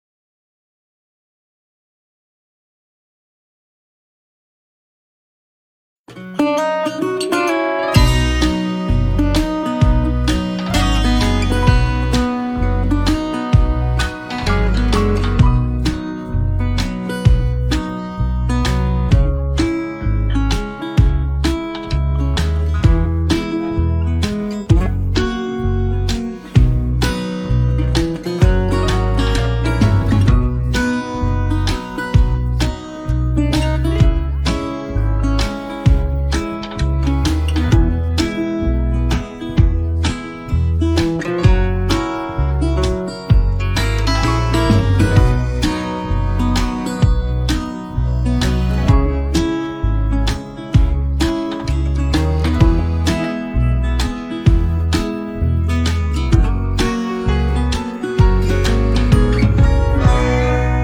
Karaoke Song